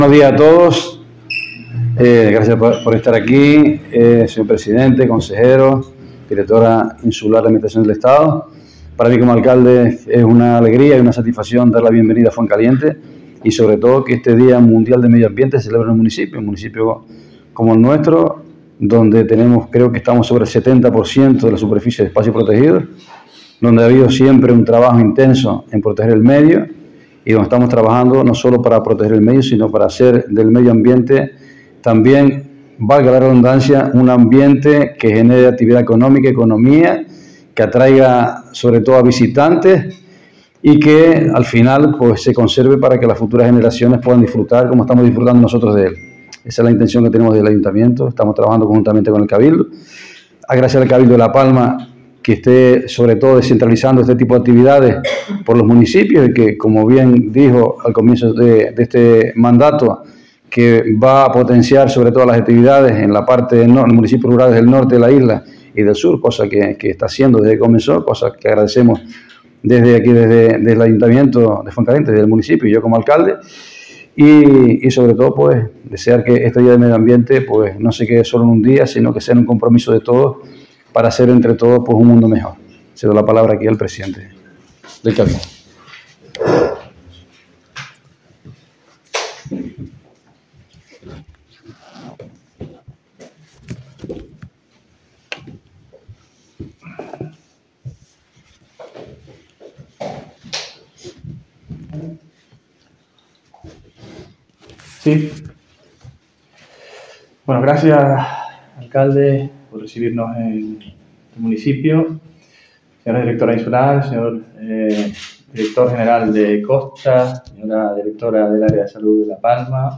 Lectura de la Declaración Institucional Medio Ambiente.mp3